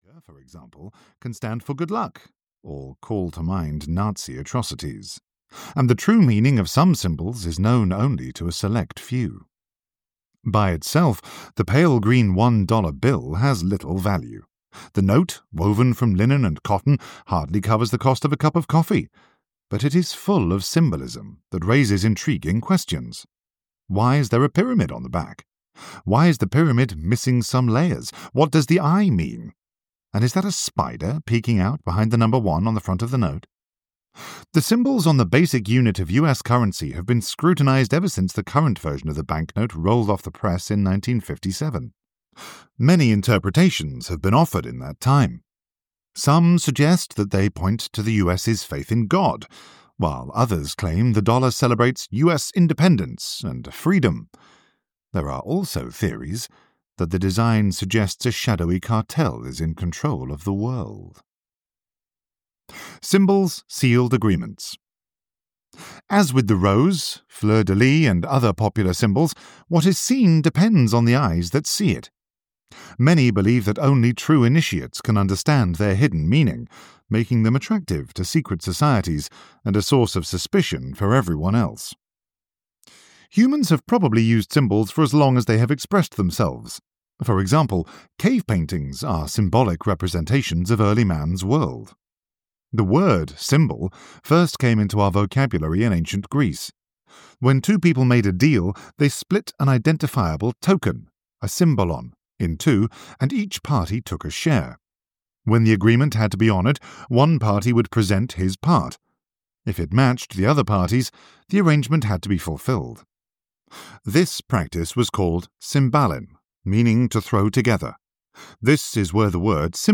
Secret Societies (EN) audiokniha
Ukázka z knihy